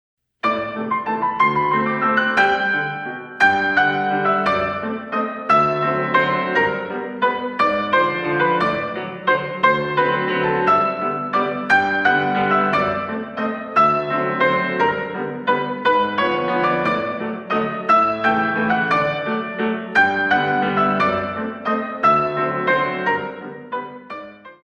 64 Counts